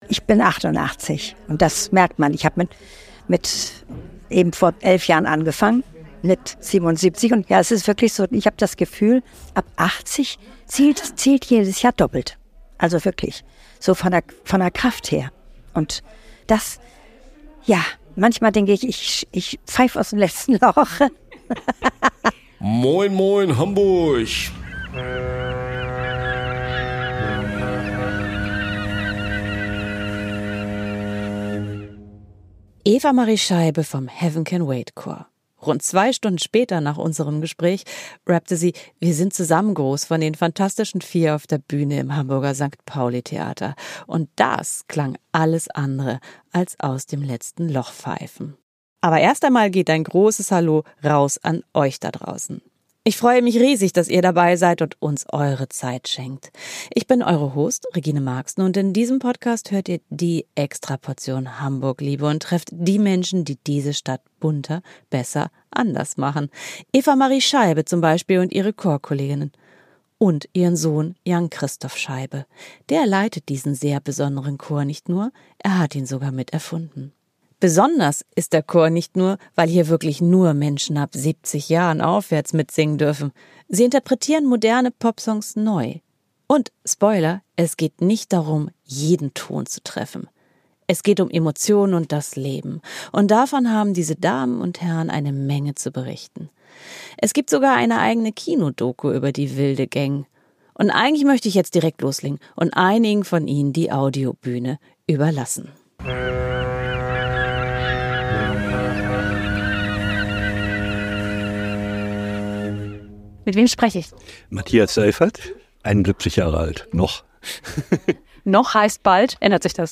Bei Heaven can wait singen nur Menschen ab 70 Jahren aufwärts mit. Sie interpretieren moderne Popsongs neu.
Dabei, Spoiler, geht es nicht darum, jeden Ton zu treffen.